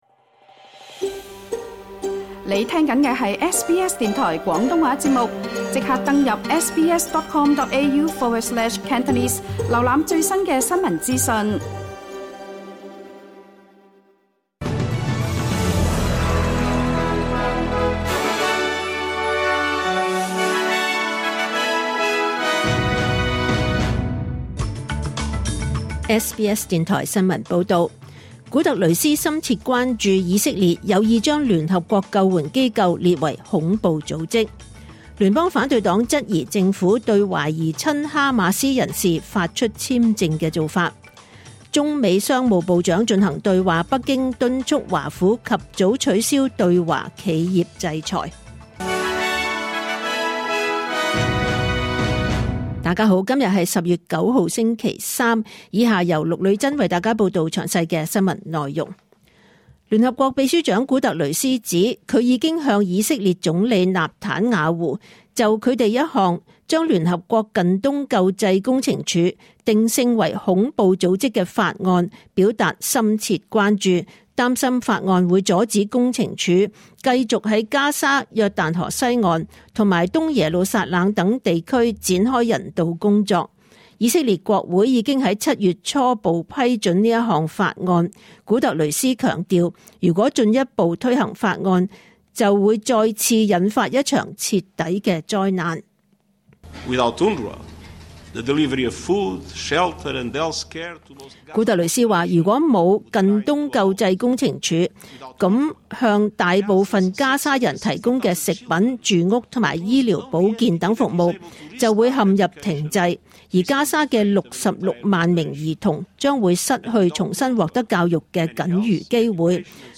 2024 年 10 月 9 日 SBS 廣東話節目詳盡早晨新聞報道。